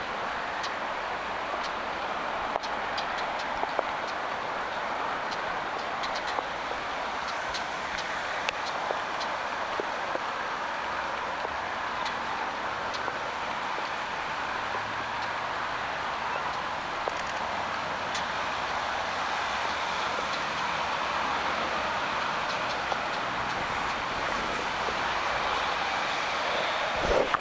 kivitaskun poikasen ääntelyä?, 2
tama_taitaa_olla_kivitaskun_poikasen_aantelya.mp3